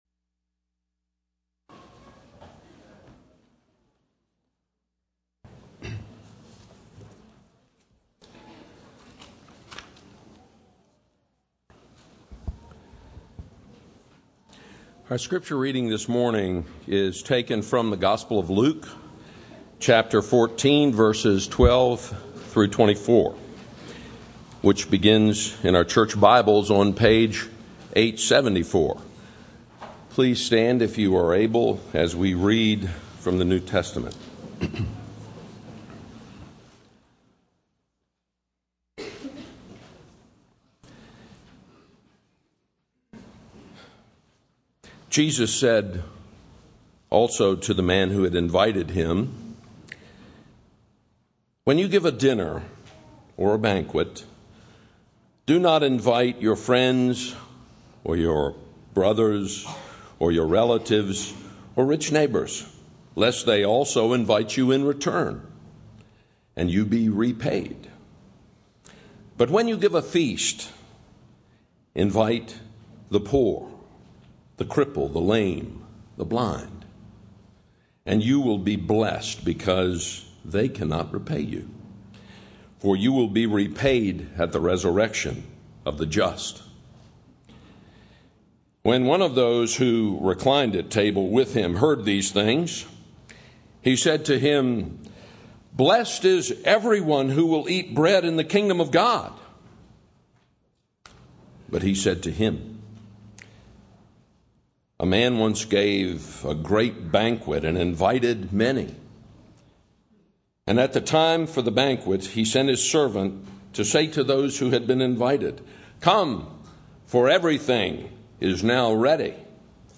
sermon-audio-10.6.13.mp3